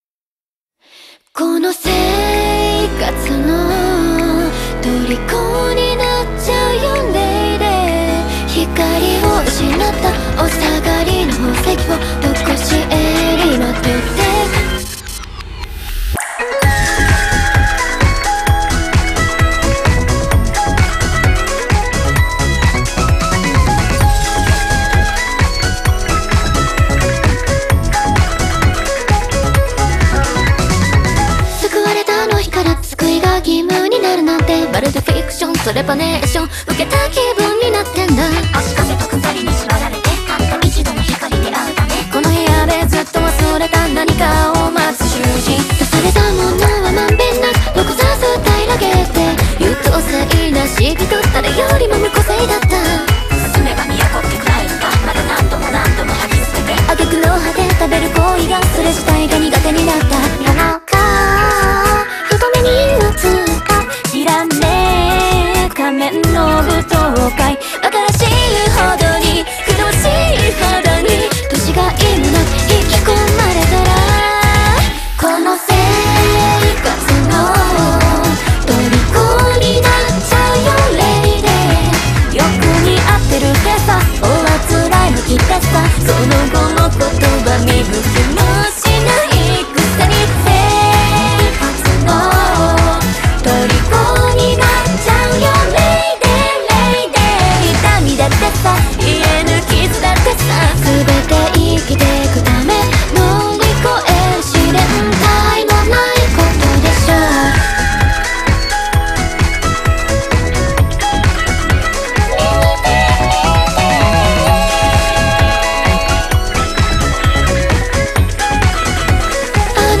BPM130
Audio QualityPerfect (High Quality)